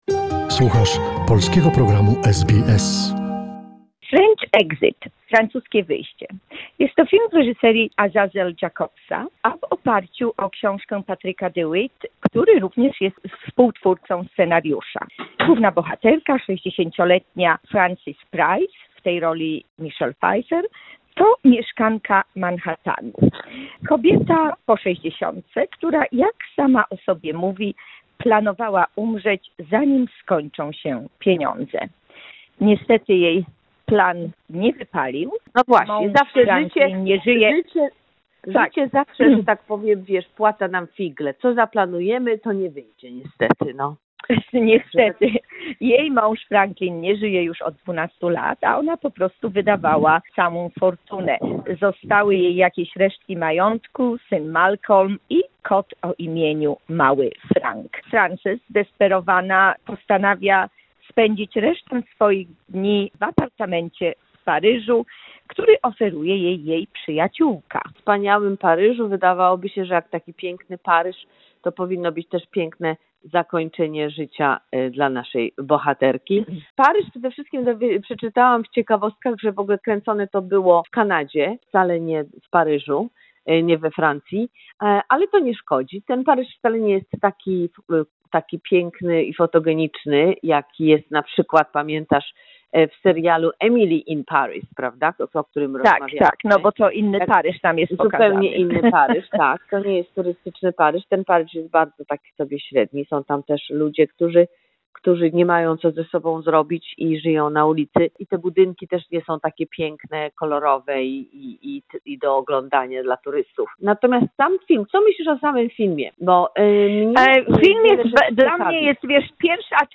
Recenzja filmu 'French Exit'